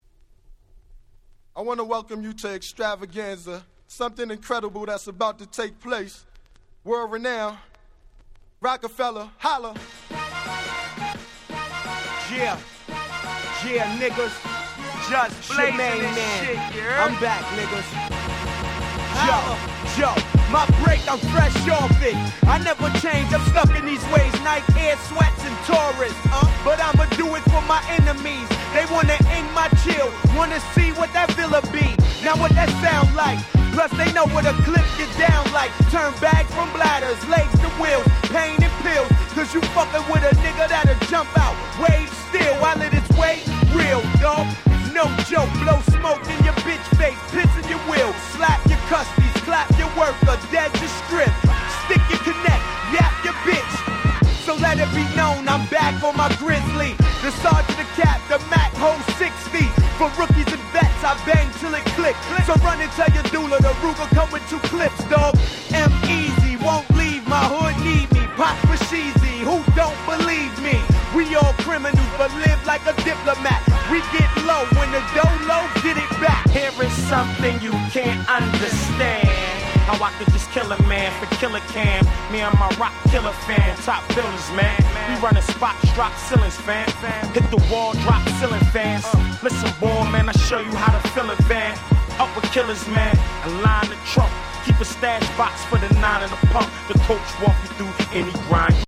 02' Super Hit Hip Hop !!